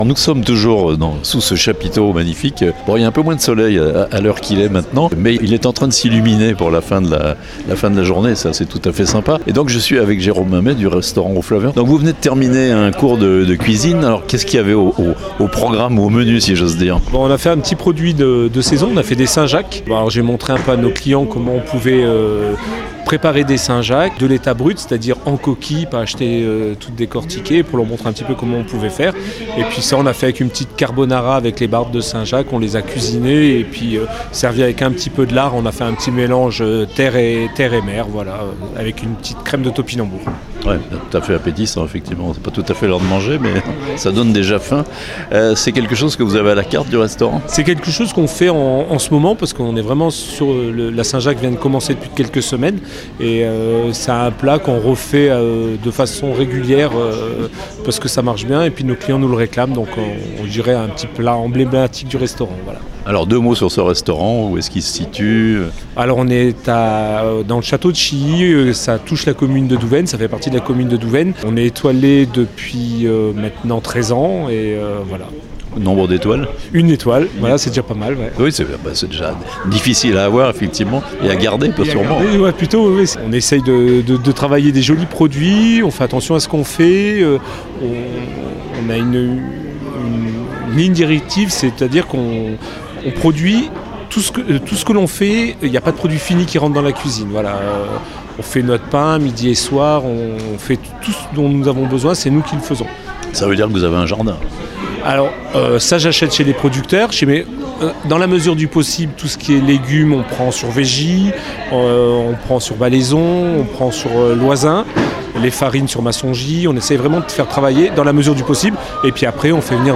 "Toques en Chablais" à Thonon, encore de belles animations aujourd'hui (interviews)